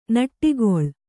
♪ naṭṭigoḷ